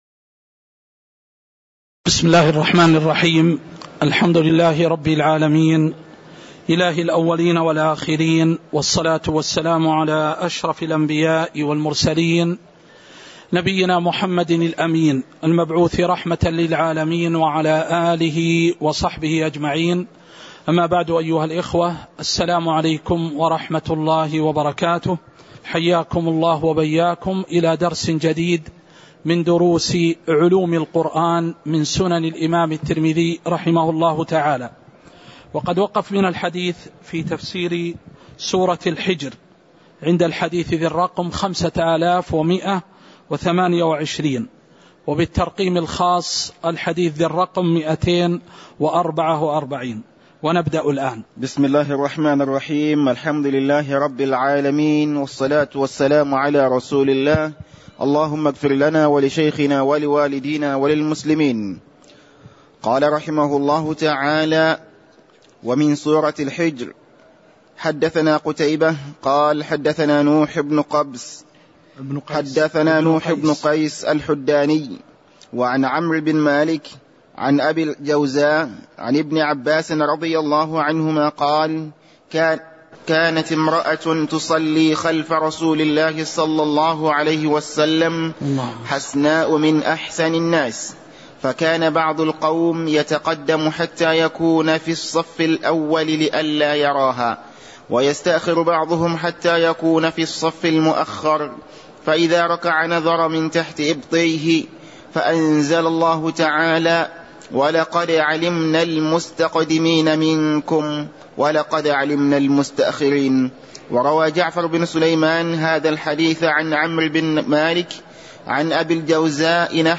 تاريخ النشر ١٤ جمادى الأولى ١٤٤٣ هـ المكان: المسجد النبوي الشيخ